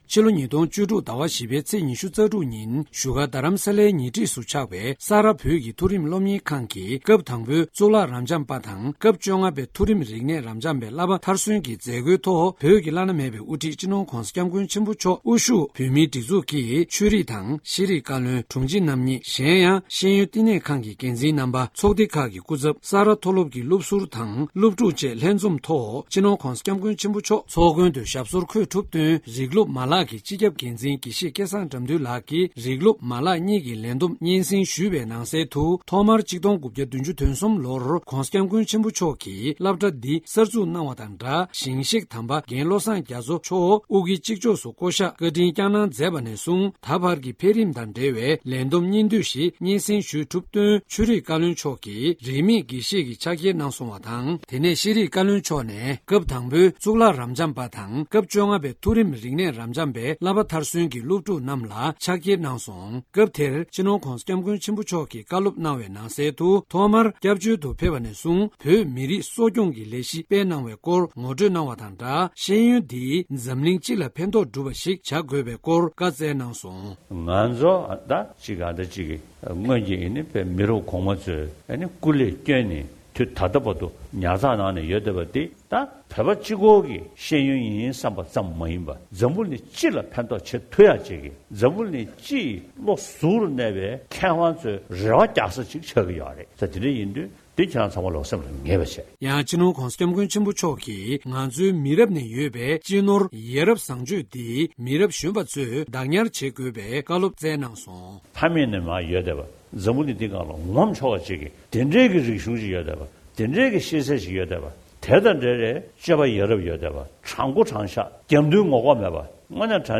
༧གོང་ས་མཆོག་གིས་ས་རཱ་མཐོ་སློབ་ཏུ་བཀའ་སློབ་གནང་བ།